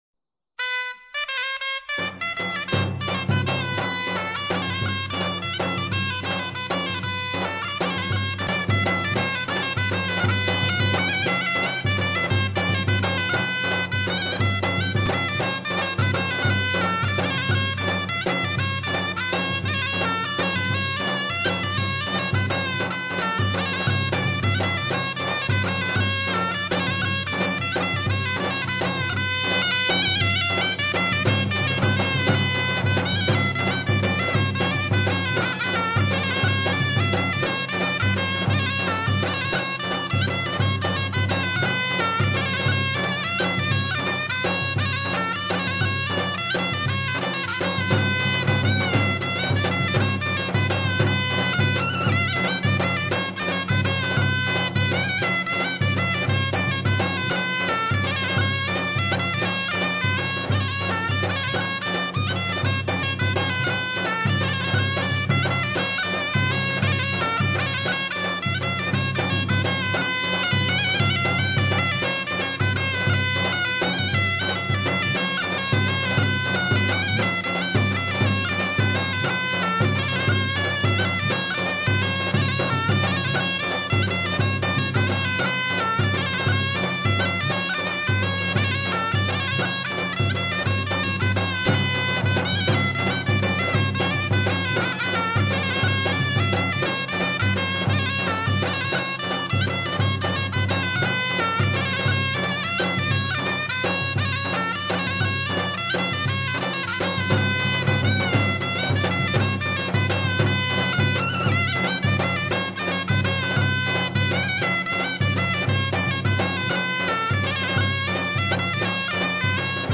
دانلود انواع آهنگ های محلی خراسانی